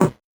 Index of /musicradar/8-bit-bonanza-samples/VocoBit Hits
CS_VocoBitC_Hit-06.wav